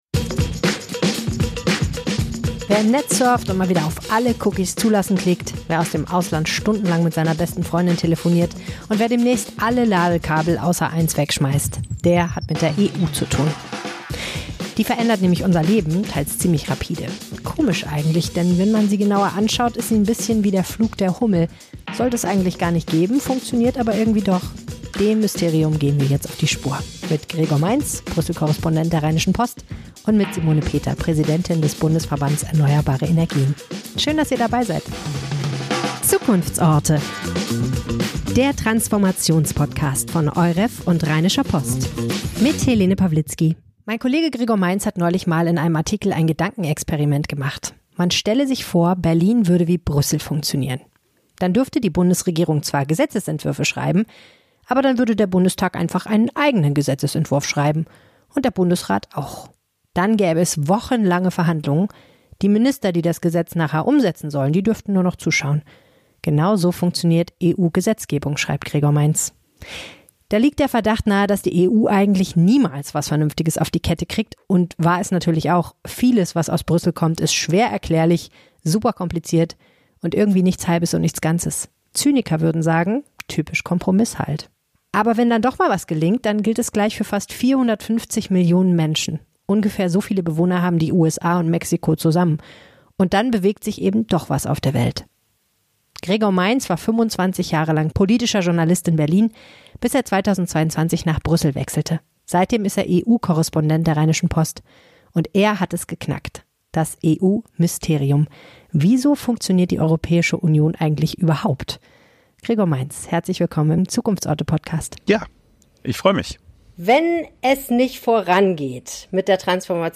Zwei Experten im Gespräch.